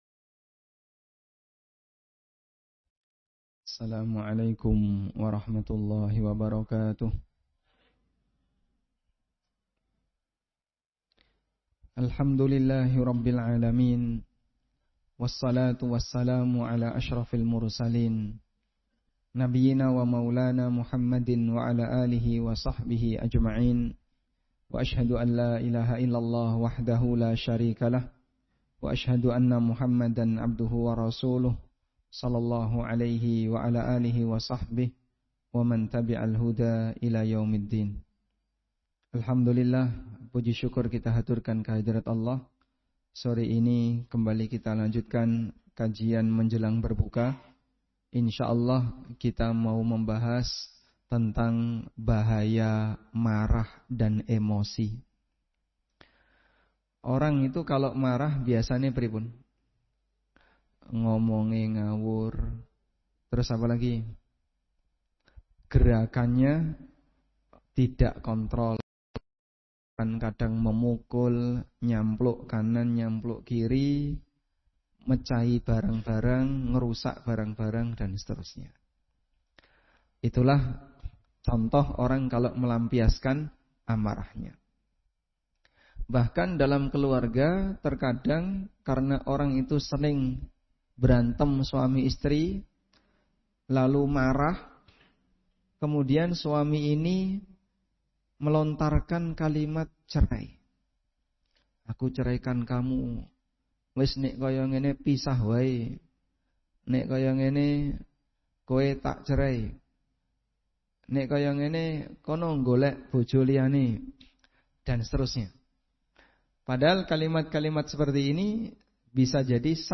Kajian ini memaparkan bahaya laten dari kemarahan serta solusi syar'i untuk meredamnya.